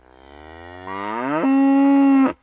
sounds_cow_moo_02.ogg